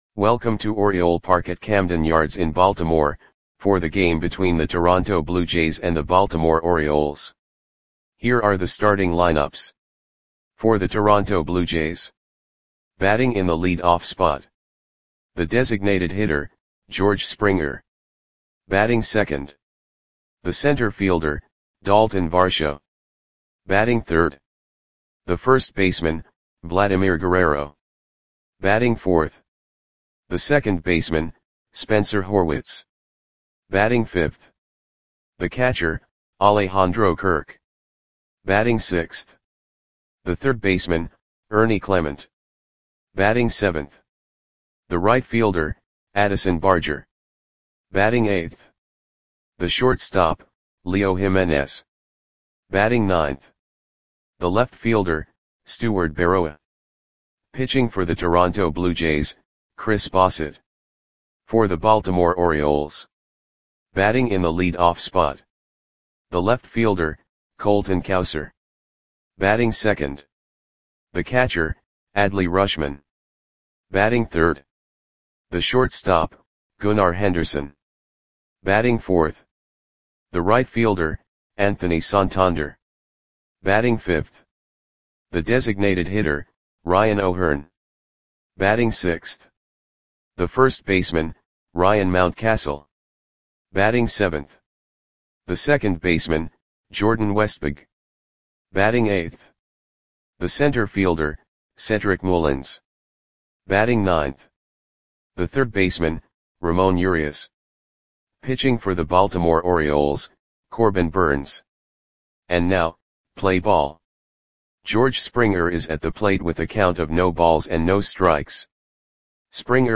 Audio Play-by-Play for Baltimore Orioles on July 30, 2024
Click the button below to listen to the audio play-by-play.